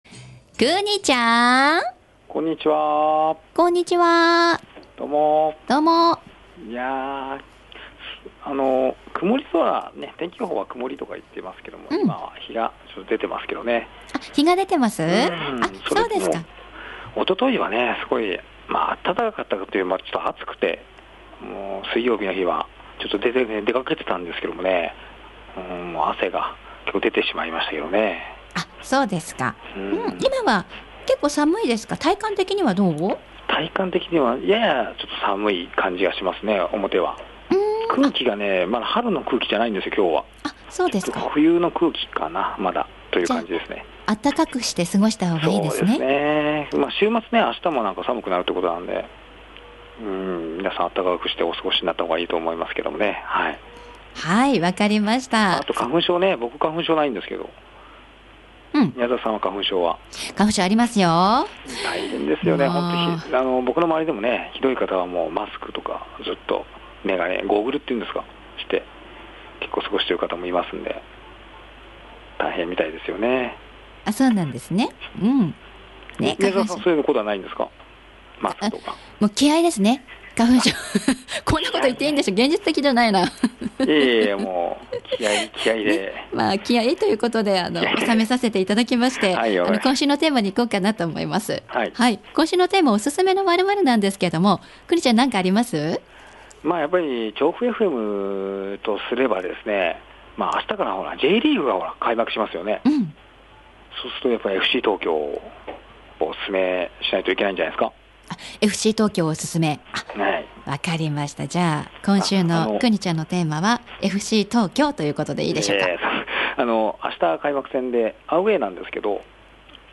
今週は以前(2014年9月)にもお伺いしたブックカフェデンさんにお邪魔しました。